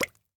bubble3.ogg